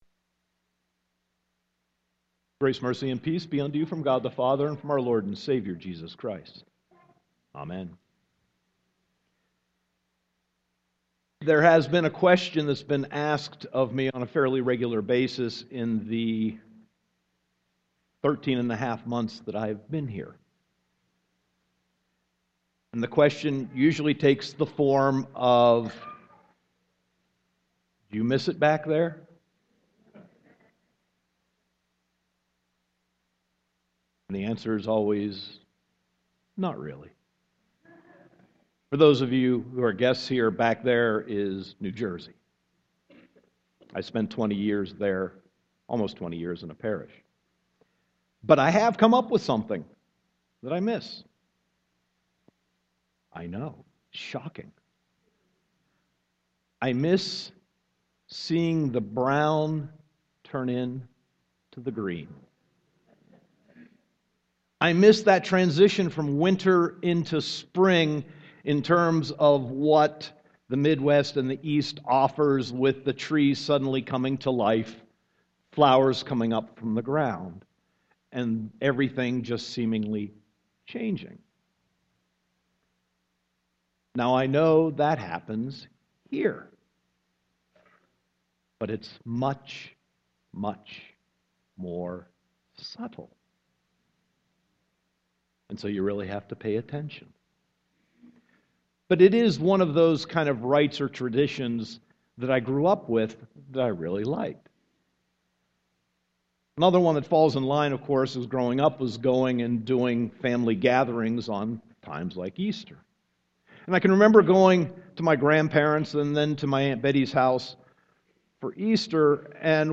Sermon 4.12.2015